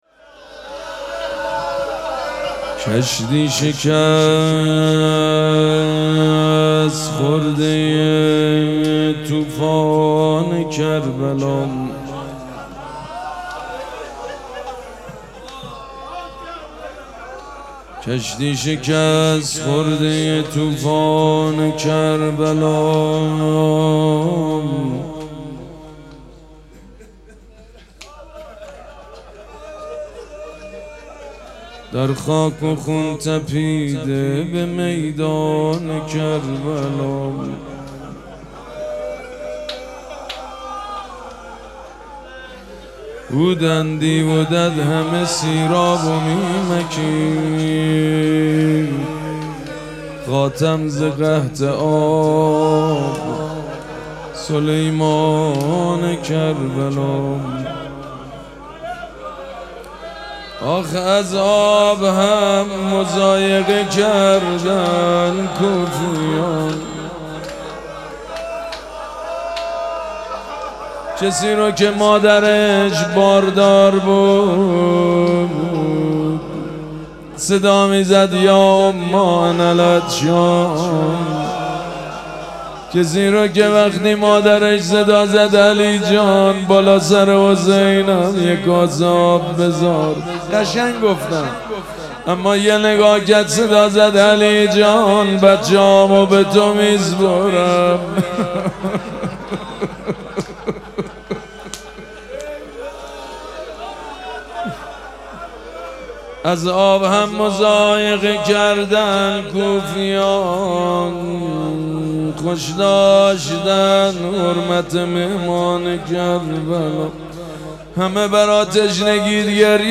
مراسم عزاداری شب شهادت حضرت زهرا سلام‌الله‌علیها
روضه
حاج سید مجید بنی فاطمه